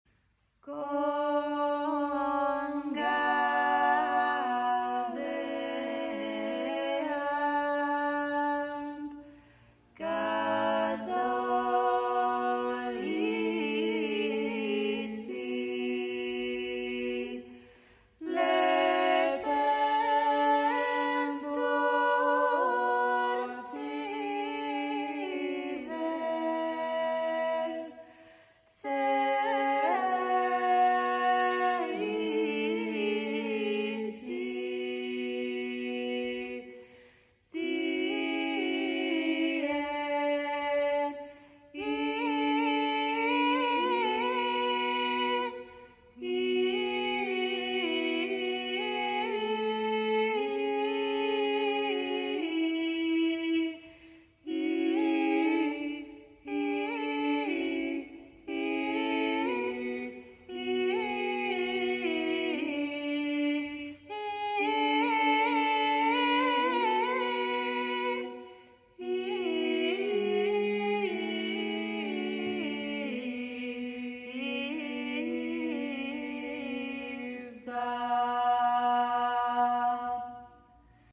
Upload By Idrîsî Ensemble
12th century harmony practice, an